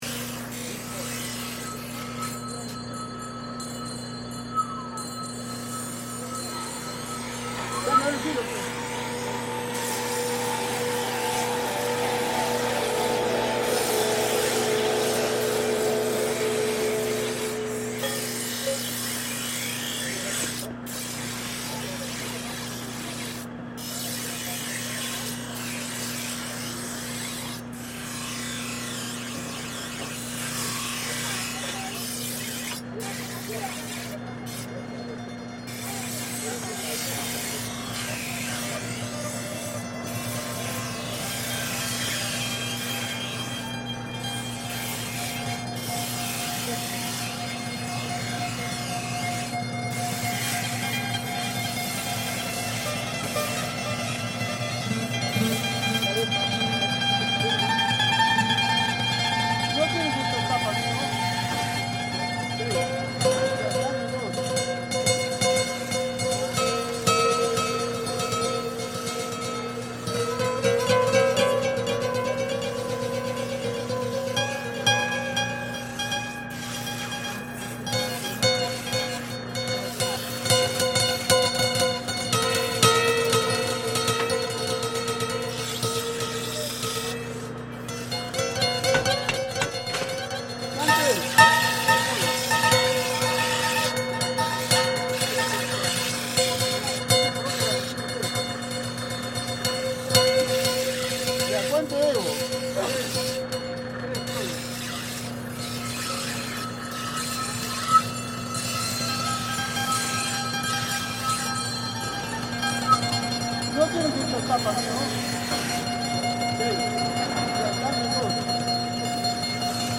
Arequipa knife sharpeners reimagined